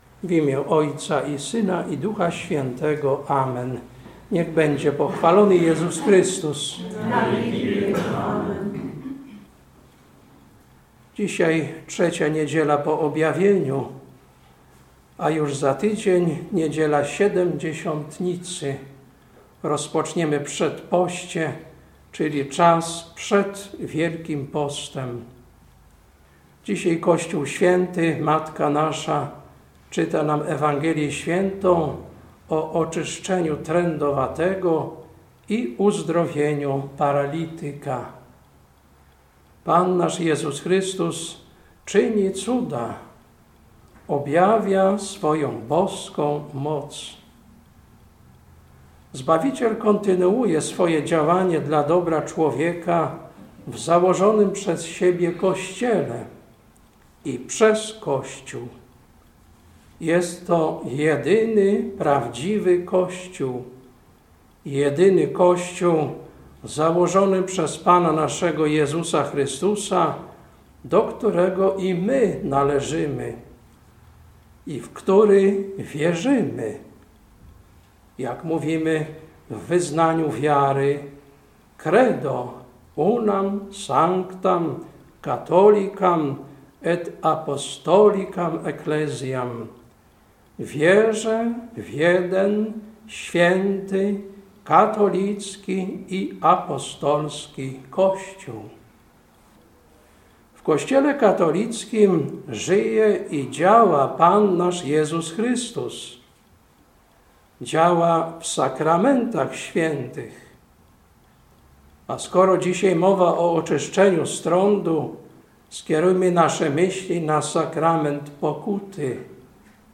Kazanie na III Niedzielę po Objawieniu, 21.01.2024 Ewangelia: Mt 8, 1-13